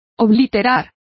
Complete with pronunciation of the translation of obliterate.